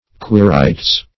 Quirites \Qui*ri"tes\ (kw[i^]*r[imac]"t[=e]z), n. pl. [L., fr.